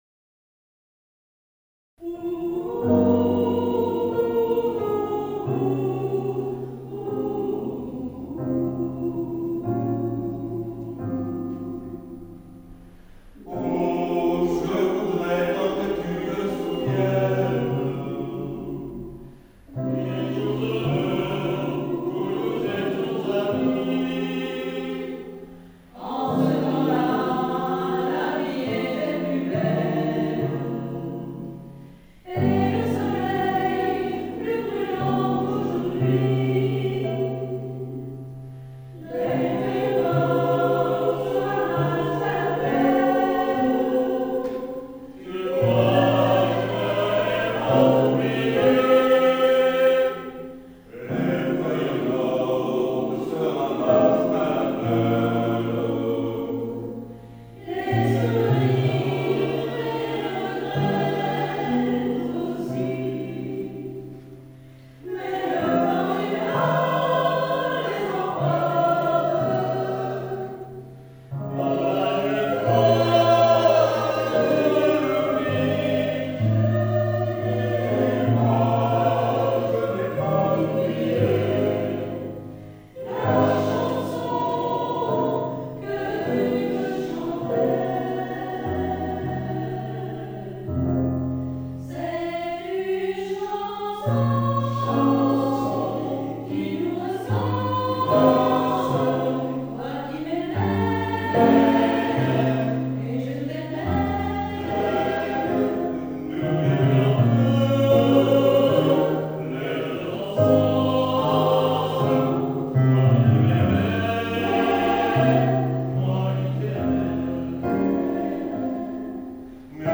Arrangement vocal